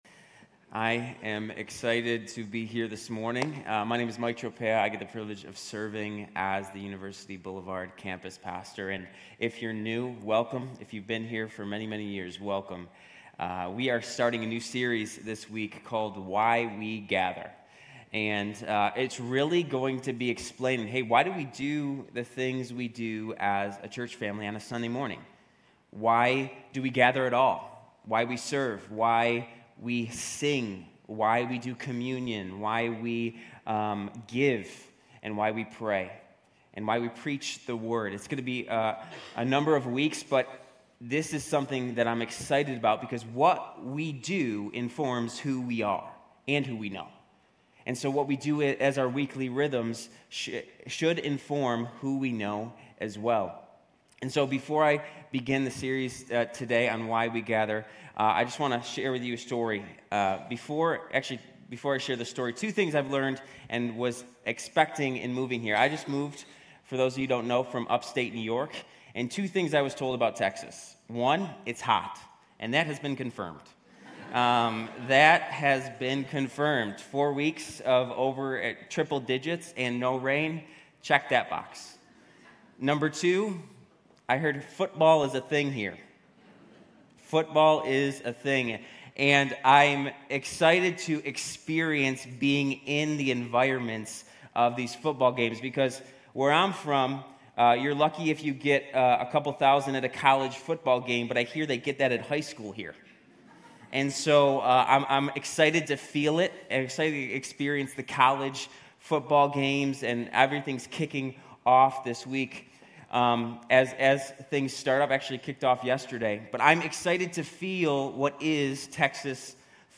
Grace Community Church University Blvd Campus Sermons Why We Gather/Serve Aug 27 2023 | 00:34:20 1x 00:00 / 00:34:20 Subscribe Share RSS Feed Share Link Embed
GCC-UB-August-27-Sermon.mp3